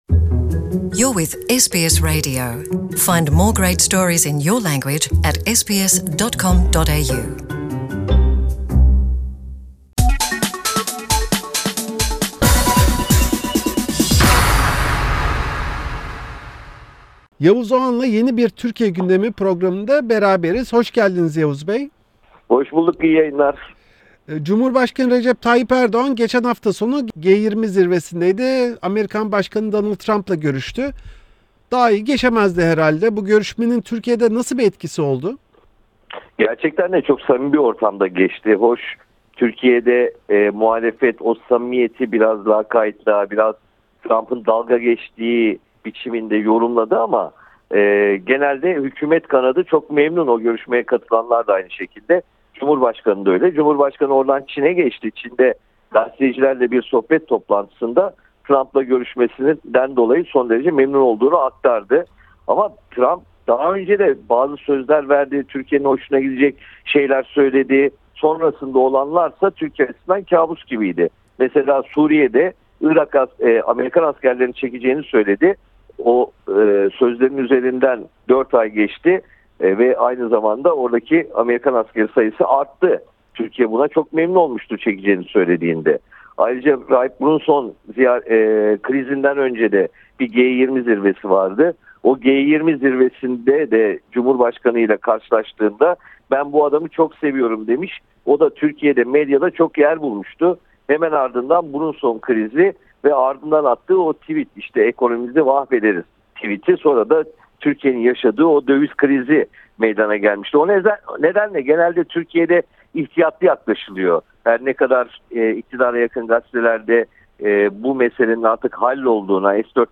Türkiye'de gündemin merkezinde ABD'yle gerilen ilişkiler ve bunun olası sonuçları var. Gazeteci Yavuz Oğhan'la, G20 zirvesinde, Trump Erdoğan görüşmesindeki sıcak hava ve sonrasındaki gelişmeler hakkında konuştuk.